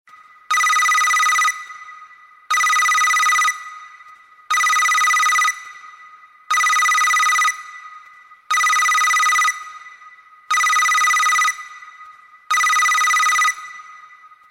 alarm_iphone.mp3